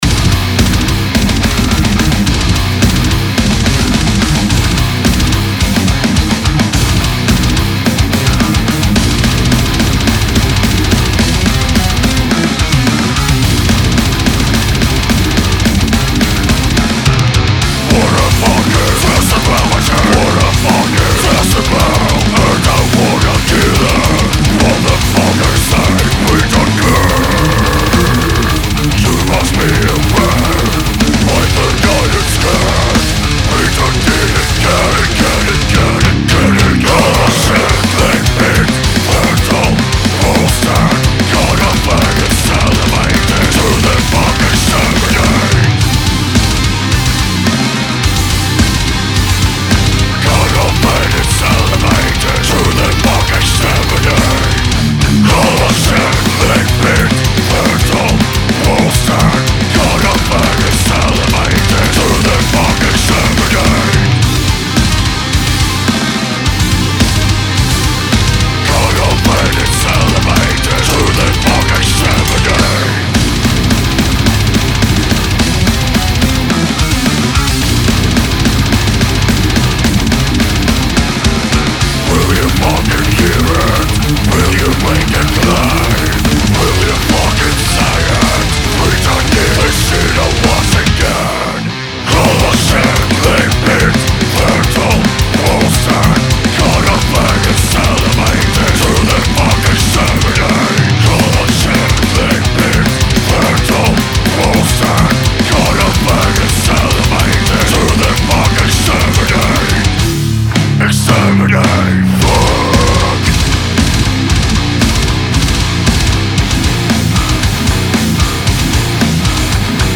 техно брутал дет
я там реально оч быстрое соло сыграл
весь секстольный пассаж